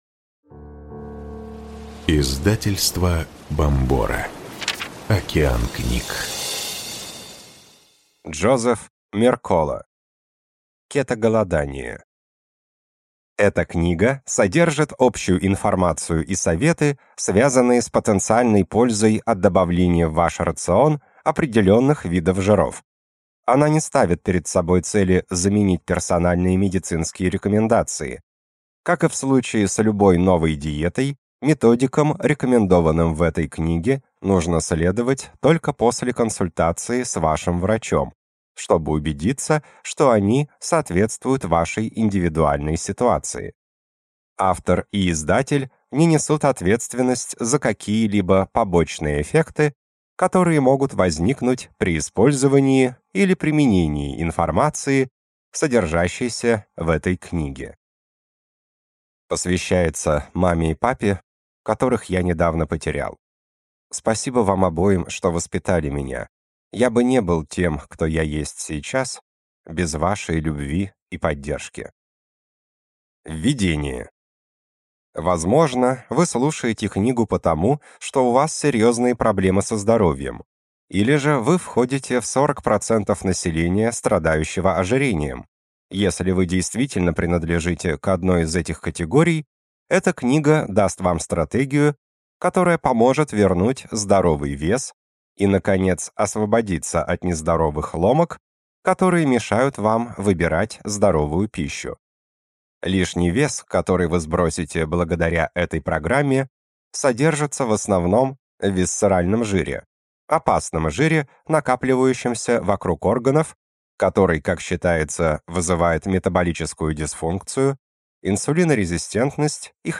Аудиокнига Кето-голодание.